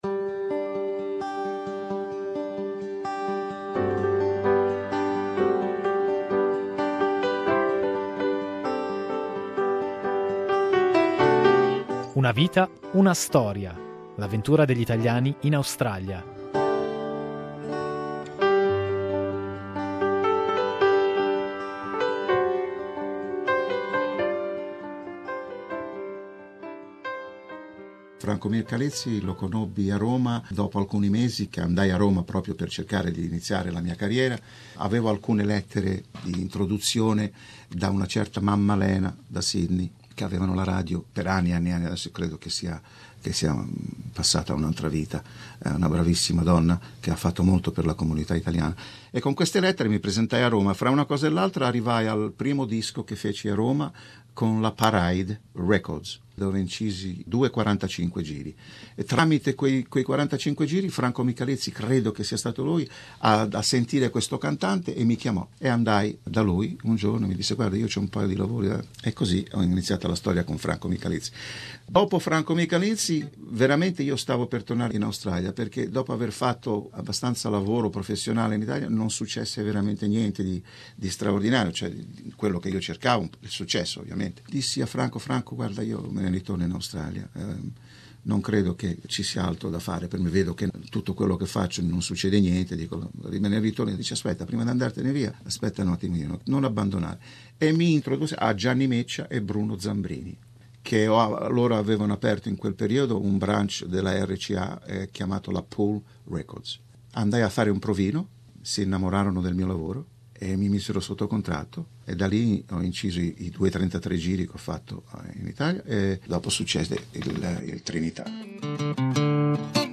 Nella seconda parte della nostra conversazione